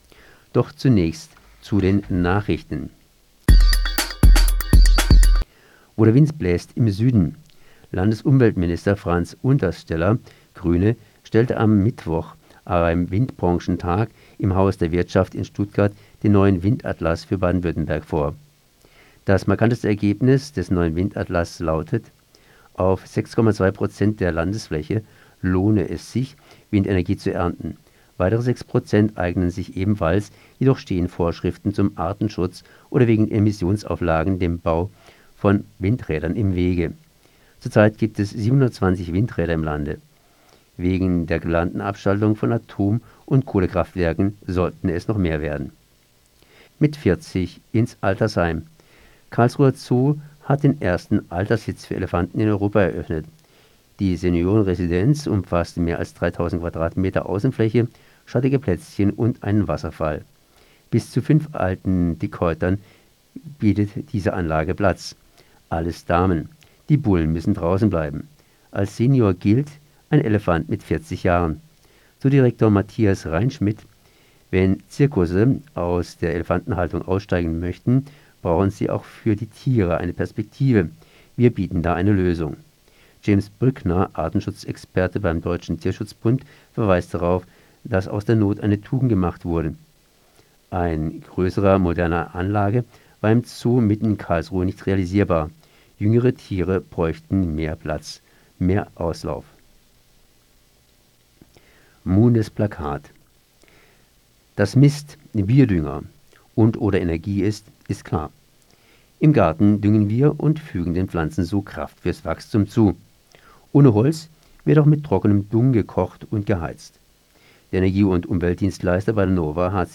Fokus Südwest 30.05.2019 Nachrichten